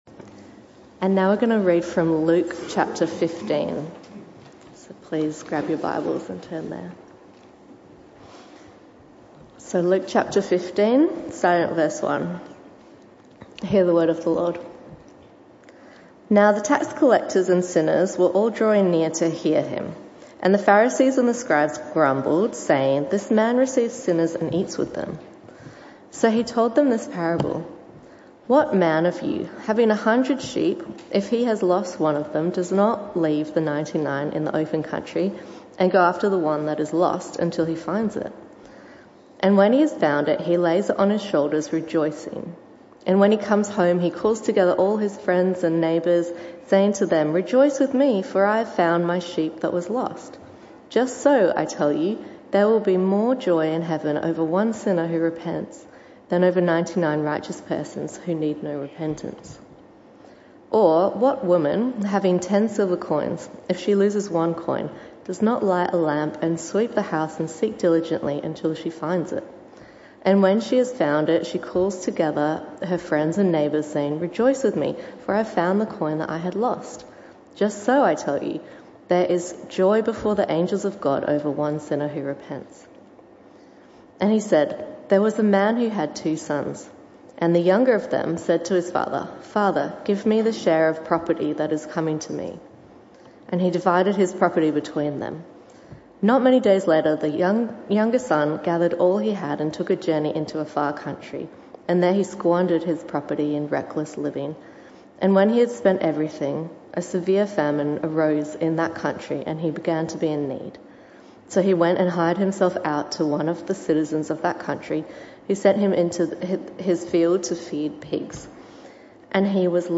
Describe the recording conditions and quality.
This talk was a one-off talk in the PM Service.